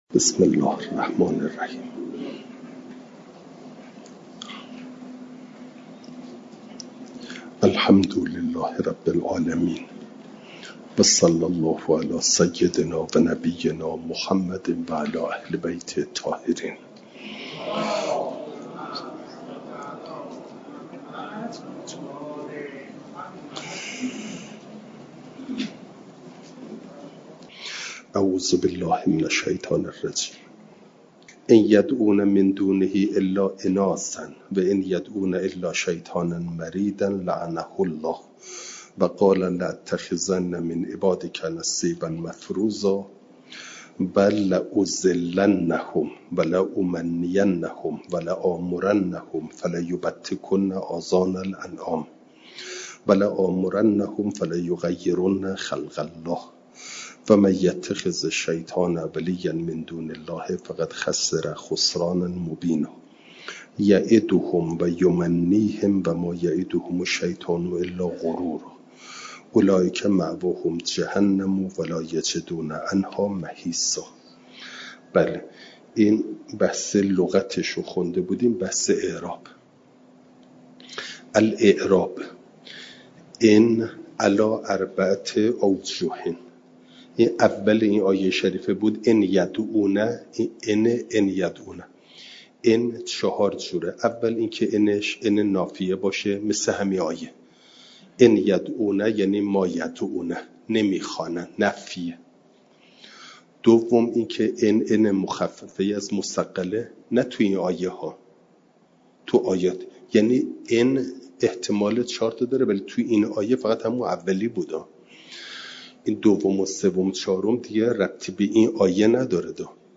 جلسه سیصد و نود و دوم درس تفسیر مجمع البیان